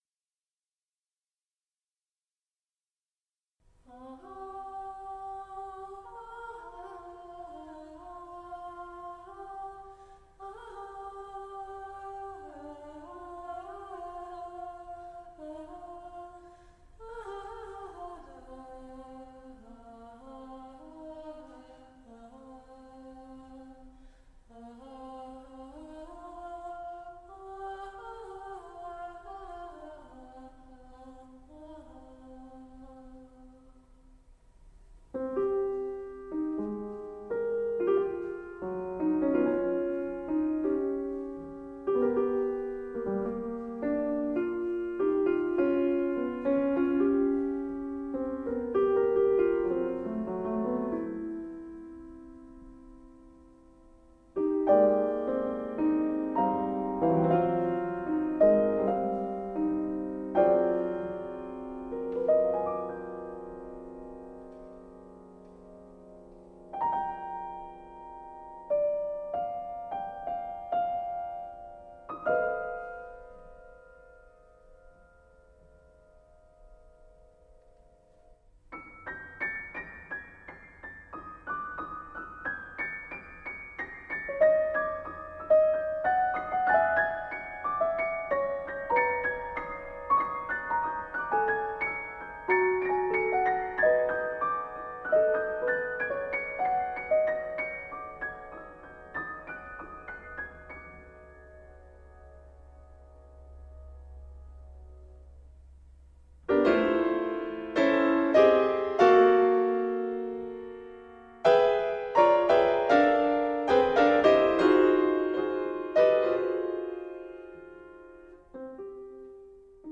for Percussion and Piano